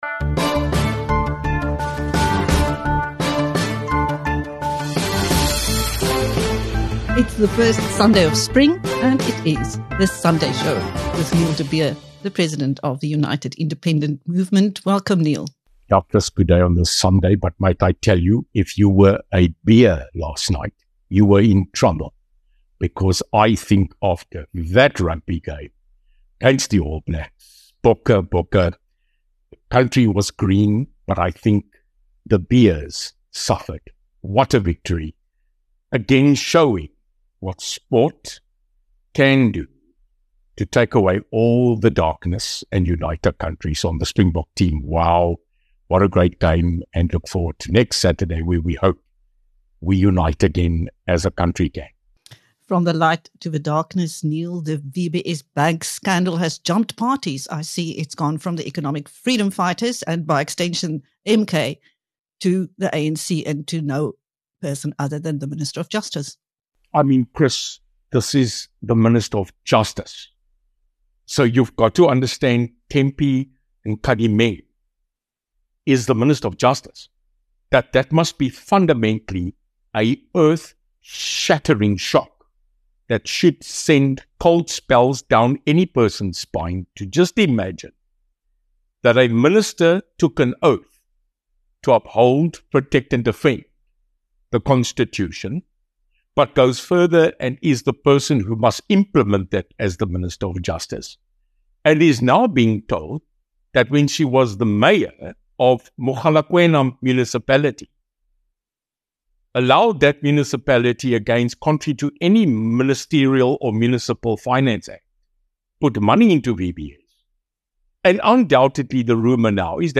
In his weekly Sunday show with BizNews, Neil de Beer, the President of the United Independent Movement (UIM), gives his analysis of a wide range of controversies: the VBS Bank scandal that has spread from the Economic Freedom Fighters (EFF) - and by extension MK - to the African National Congress (ANC) Minister of Justice; the “Walking Dead” like Shabir Shaik who was released from prison for being terminally ill 15 years ago; the latest gun battles between the police and wanted suspects; leaked info on the Mpumalanga military training camp - and the discovery of one in the Western Cape; the increasing crackdown on illegal immigrants; the battle of Tshwane’s Democratric Alliance (DA) Mayor to hold on to his job while there is dissent in the ranks of ActionSA and a huge backlash against the party for wanting to oust him; the silence over SA’s “moer toe” SOE’s; and how old political foes - who used so much “vile and poison” against each other before the election - are now cozying up...